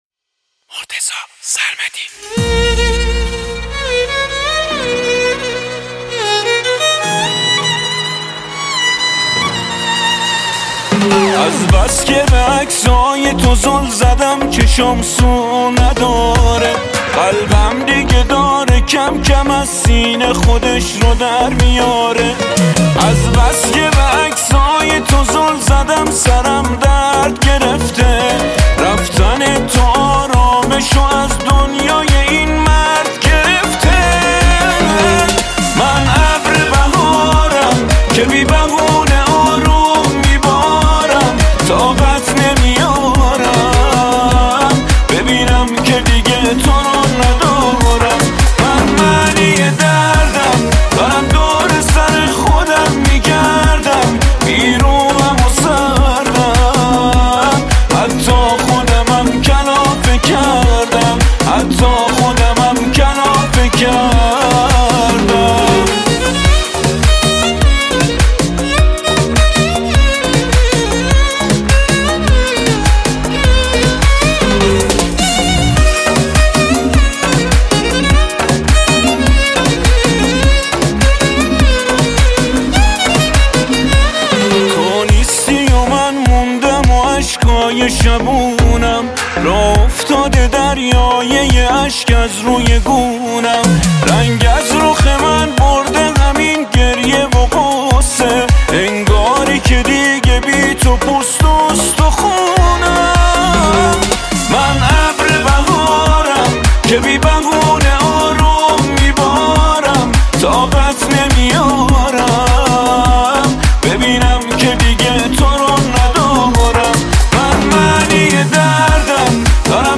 ویولن
گیتار
تک اهنگ ایرانی